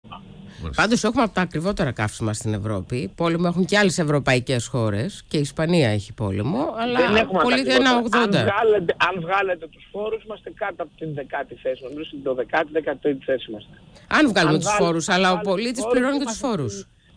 Παρεβρισκόμενος σε ραδιοφωνική εκπομπή ο υπουργός Ανάπτυξης δέχτηκε σχόλιο της δημοσιογράφου η οποία υποστήριζε πως η ελληνική τιμή καυσίμων είναι από τις υψηλότερες.